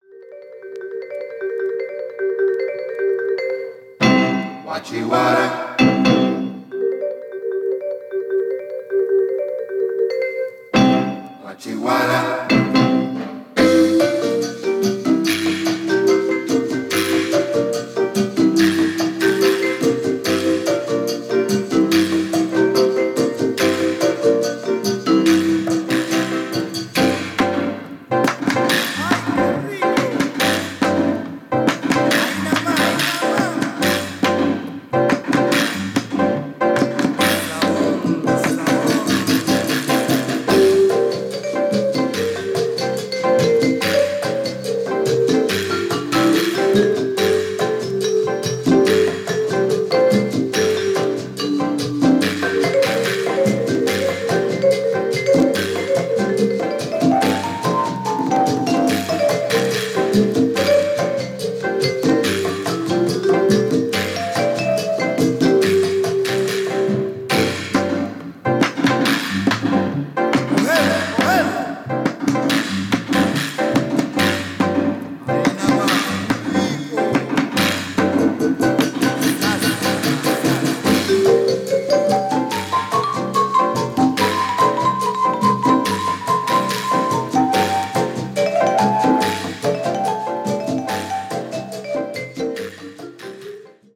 Funkyな楽曲満載なコンピレーション！！！